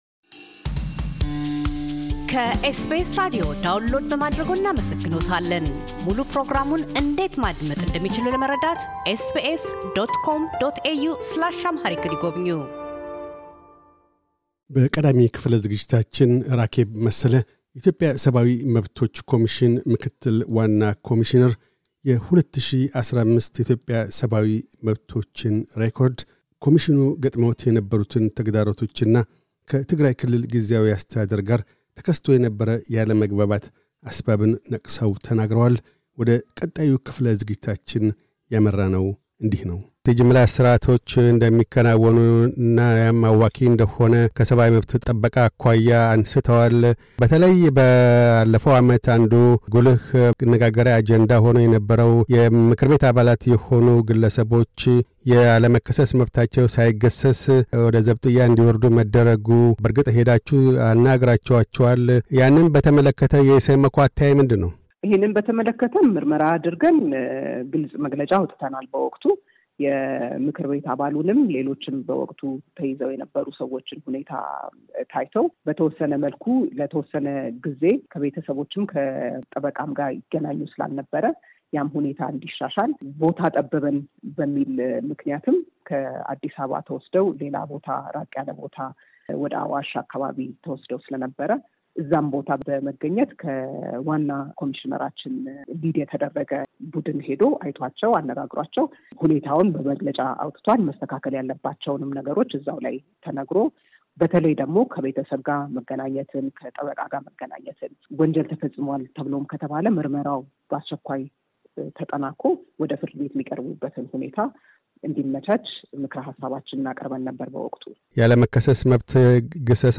ራኬብ መሰለ - የኢትዮጵያ ሰብዓዊ መብቶች ኮሚሽን ኮሚሽነር፤ የ2015 የኢትዮጵያን ሰብዓዊ መብቶች ሬኮርድና የ2016 አካሔድን አስመልክተው ይናገራሉ።